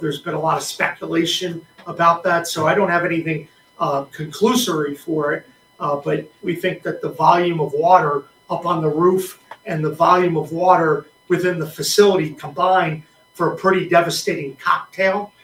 An apartment building collapsed but no injuries were reported. Governor Patrick Morrissey told reporters FEMA is in the area and the collapse continues to be investigated…